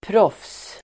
Uttal: [pråf:s]